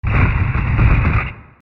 大勢が地面に倒れる 04
/ J｜フォーリー(布ずれ・動作) / J-10 ｜転ぶ　落ちる